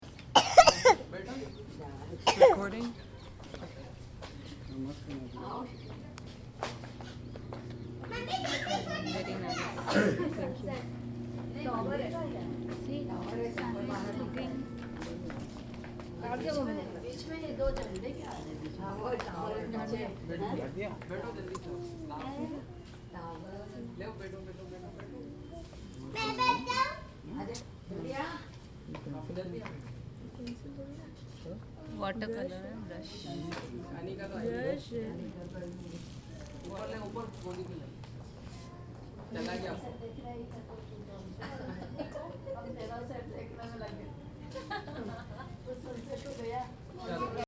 On the evening, we go to see the sunset since a garden, on an island in the middle of the lac.
sunset1.mp3